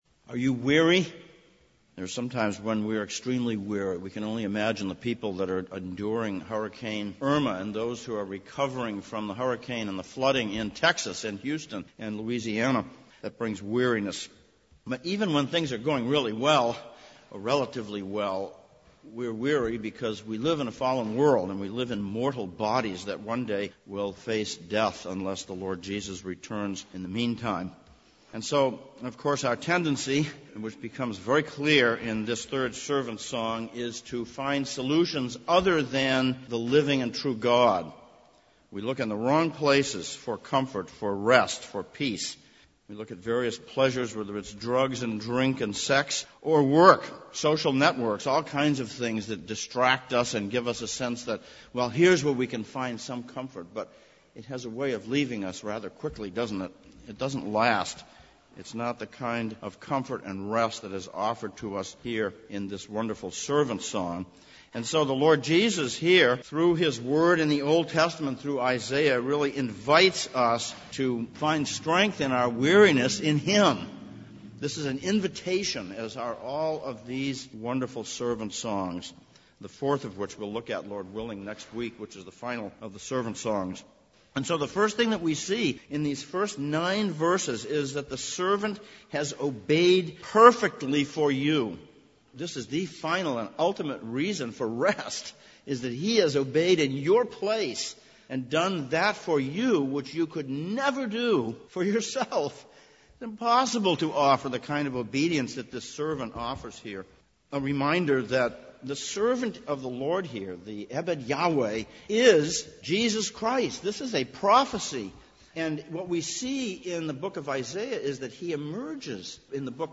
Passage: Isaiah 50:1-11, Hebrews 10:1-18 Service Type: Sunday Morning